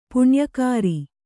♪ puṇyakāri